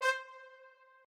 strings1_25.ogg